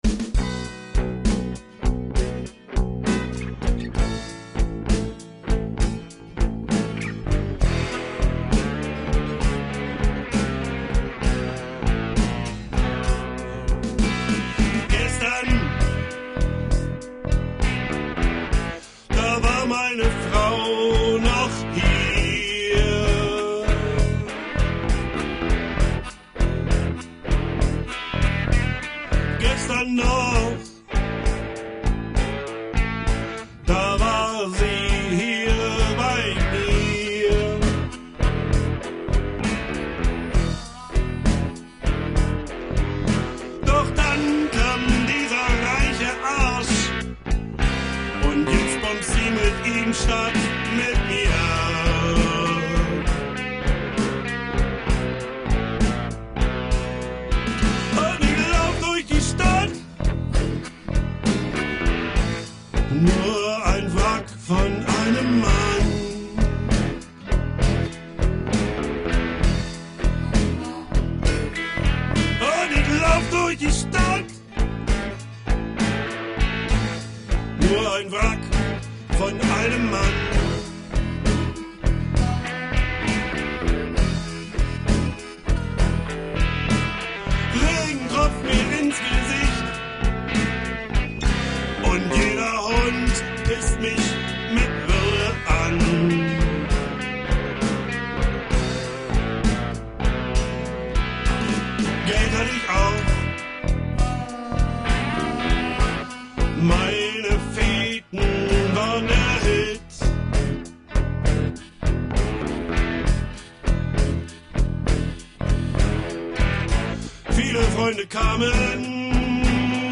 vocals, flute
bass, drums
guitar